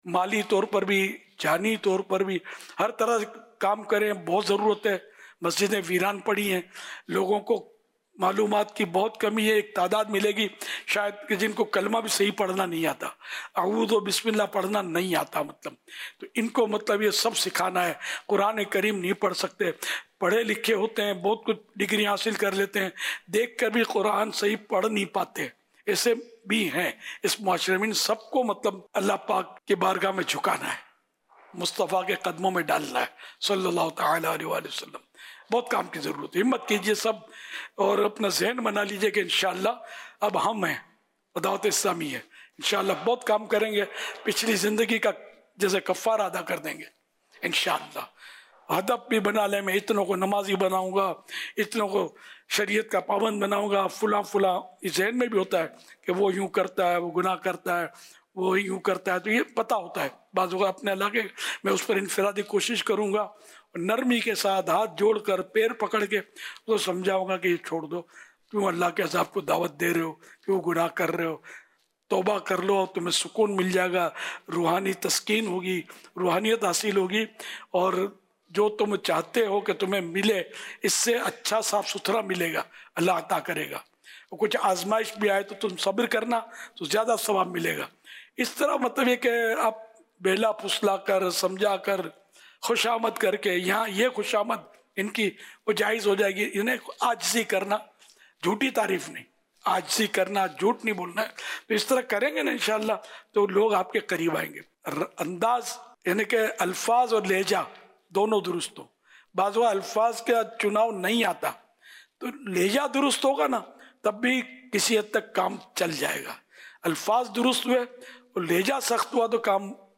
khutba